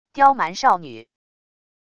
刁蛮少女wav音频